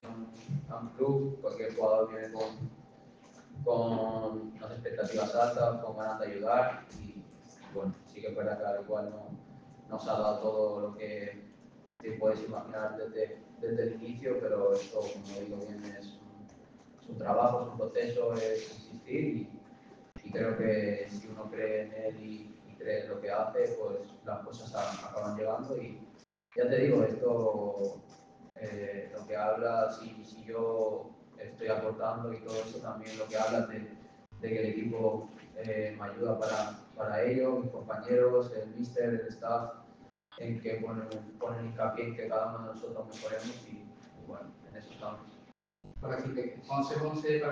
Gerard Fernández «Peque» volvió a situarse en el foco mediático tras comparecer en la sala de prensa del Estadio Jesús Navas.